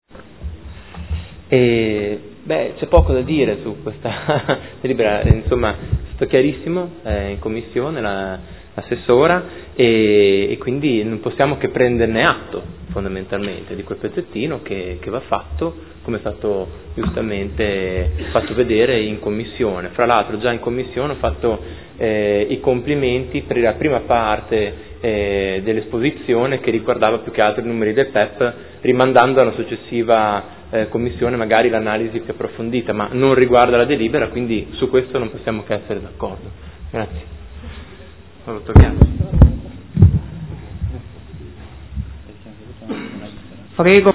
Seduta del 21/05/2015 Dichiarazione di voto.